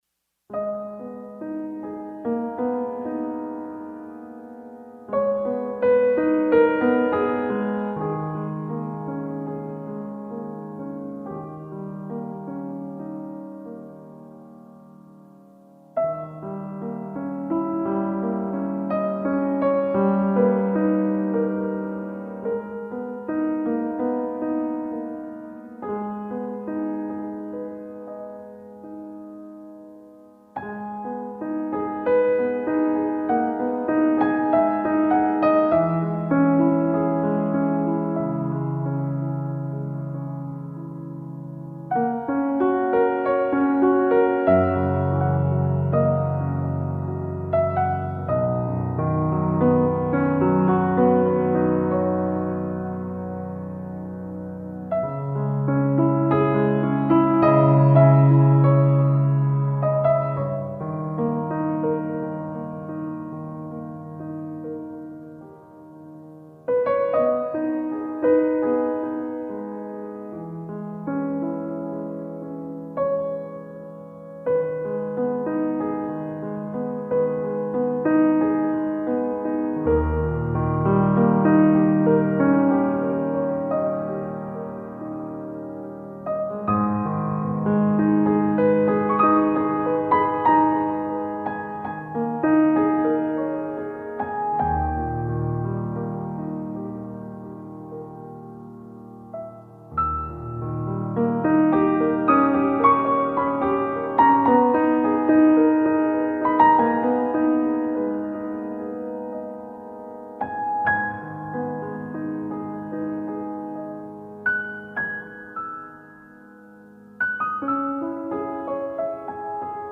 для струнных инструментов и органа